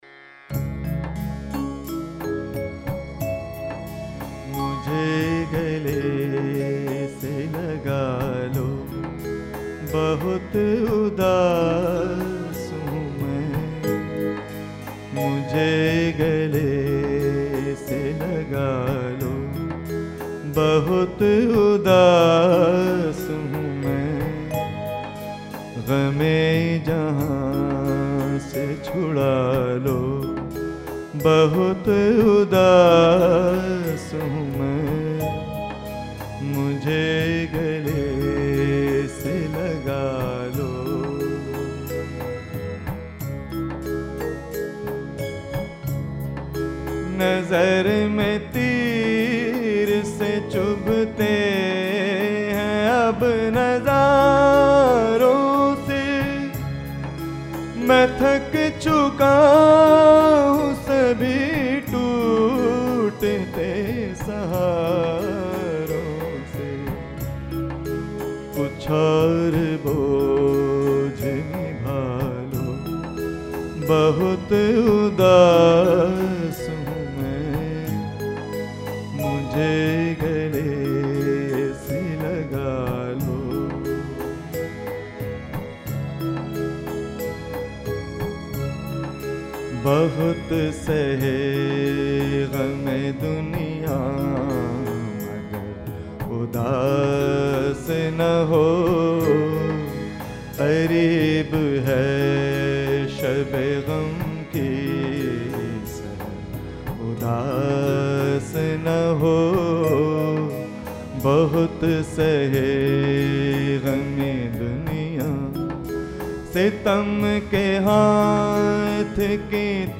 MUSIC OF THE FILM